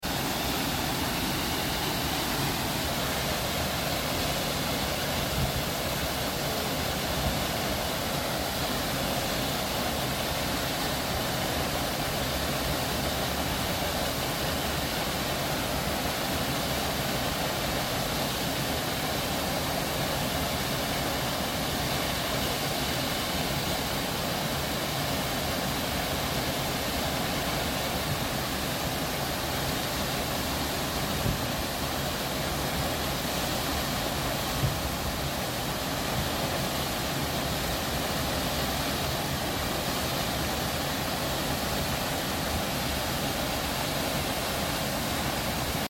listen to this healing waterfall sound effects free download